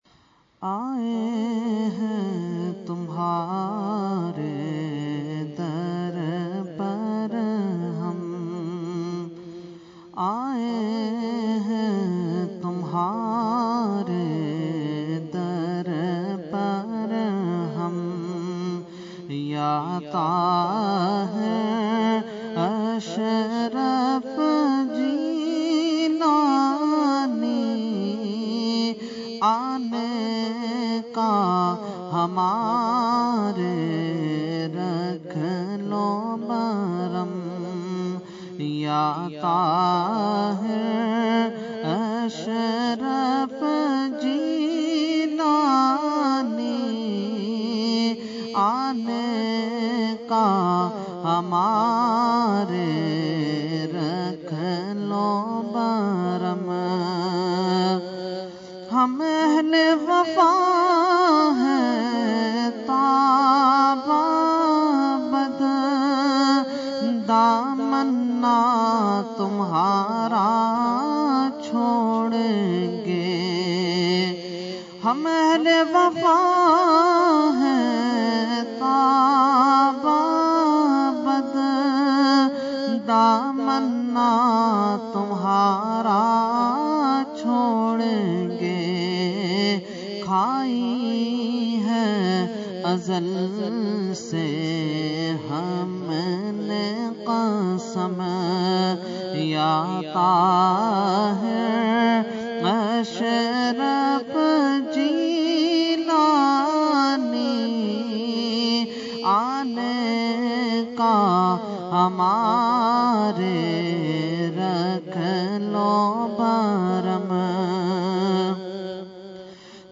Category : Manqabat | Language : UrduEvent : Urs Qutbe Rabbani 2016